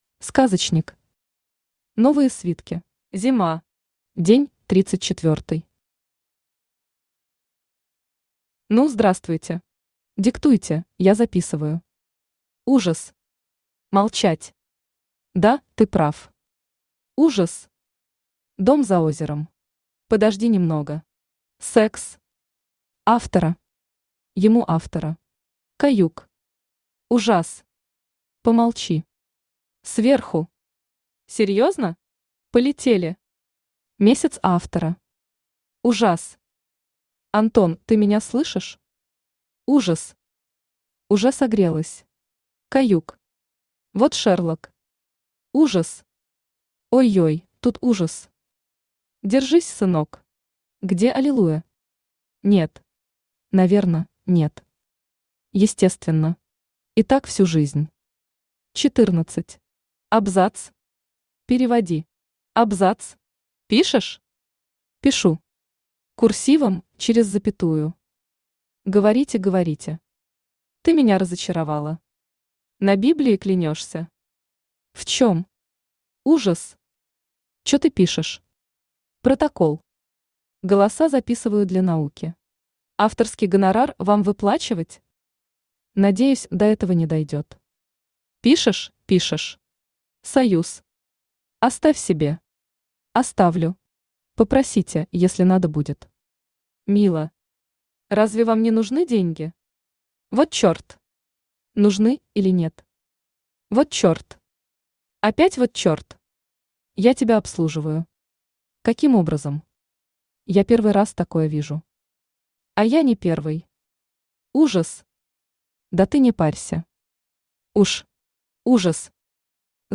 Аудиокнига Новые свитки | Библиотека аудиокниг